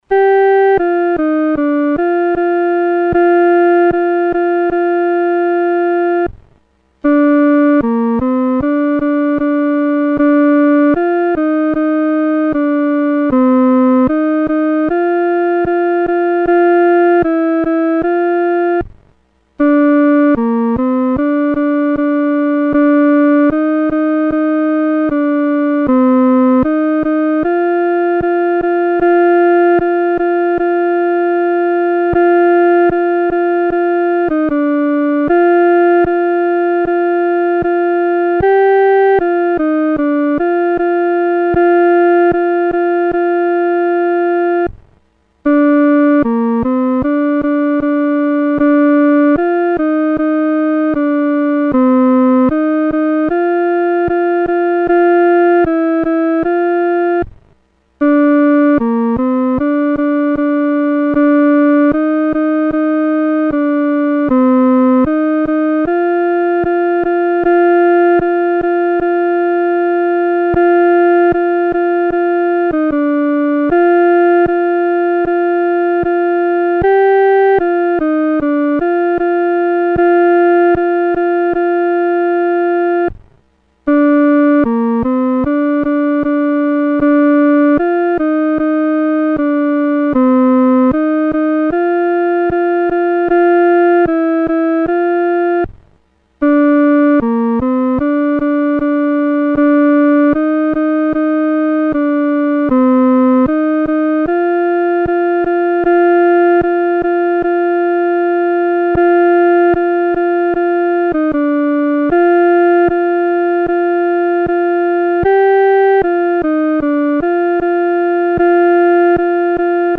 独奏（第二声）